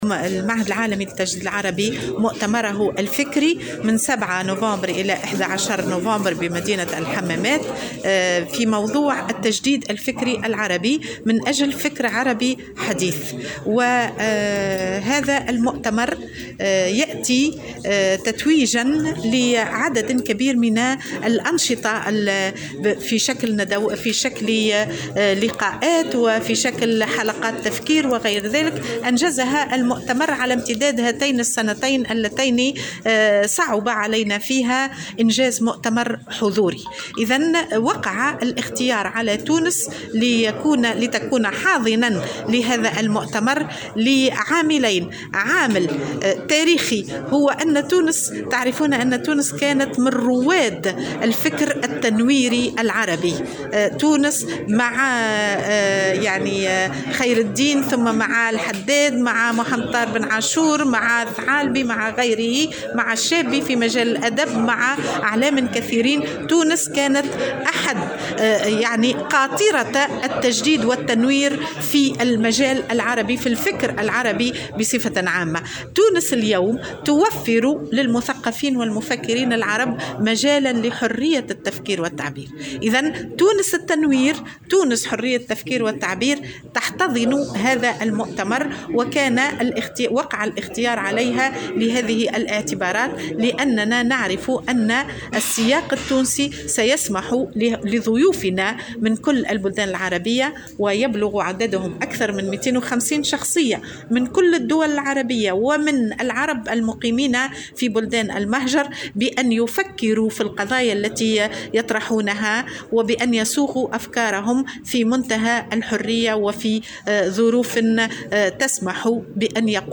وأضافت في تصريح لمراسل "الجوهرة أف أم" أن المؤتمر سيشهد حضور أكثر من 250 شخصية من جميع الدول العربية، مع تنظيم محاضرات جماعية يُقدمها عدد كبير من المثقفين العرب، إضافة إلى برمجة 175 مداخلة علمية.